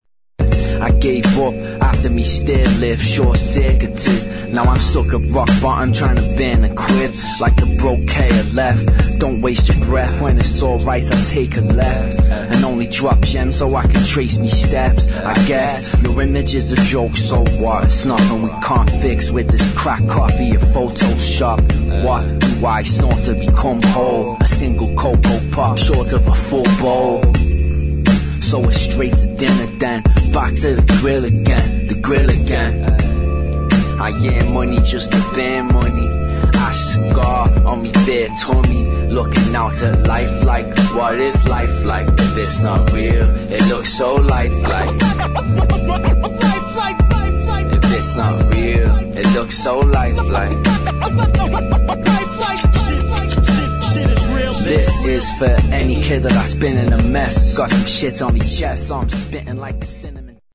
[ HIP HOP ]